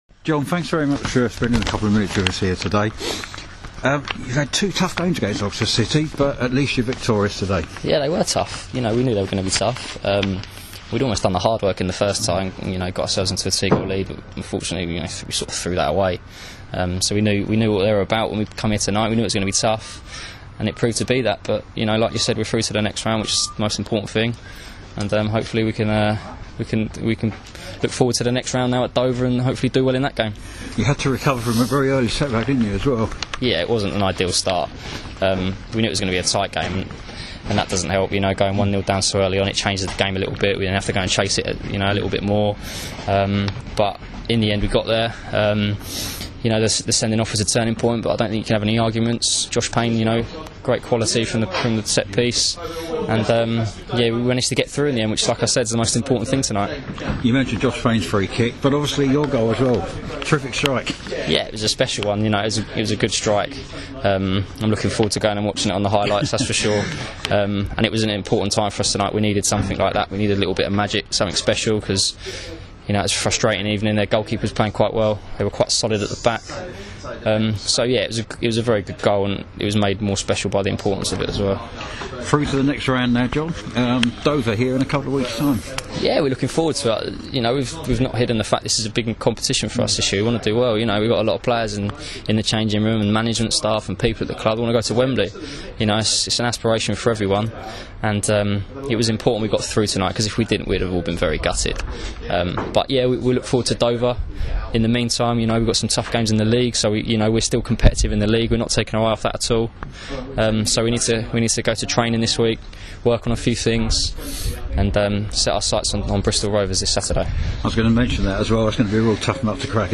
speaking after the Cards FA Trophy win against Oxford City